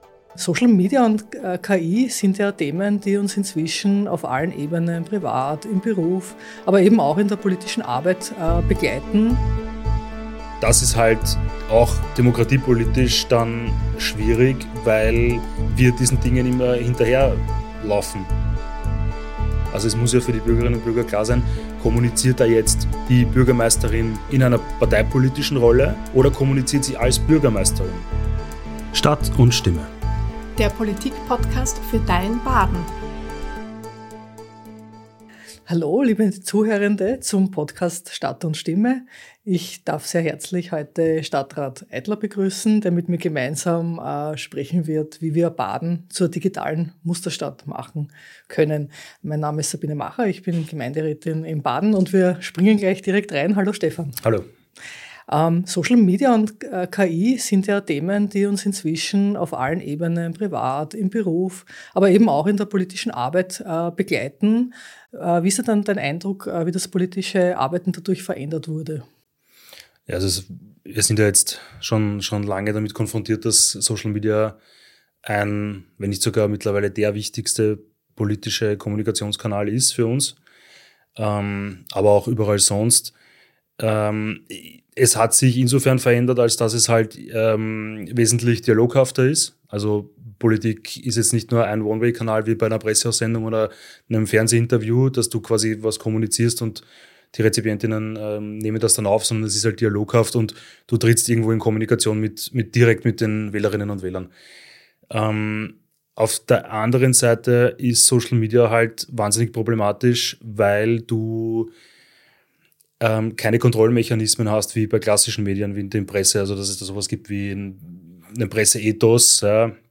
Ein Gespräch über digitale Verantwortung und die Vision einer Verwaltung, die moderne Technologien nutzt, ohne demokratische Grundsätze aus den Augen zu verlieren.